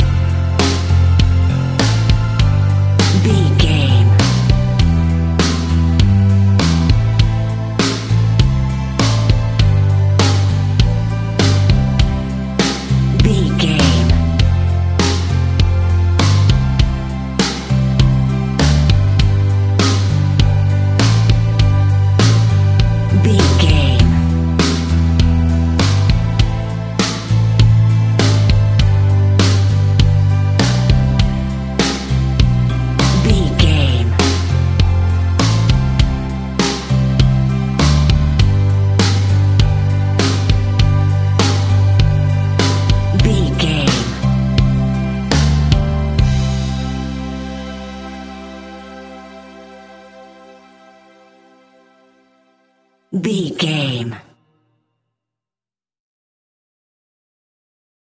Ionian/Major
Slow
calm
melancholic
smooth
soft
uplifting
electric guitar
bass guitar
drums
indie pop
instrumentals
organ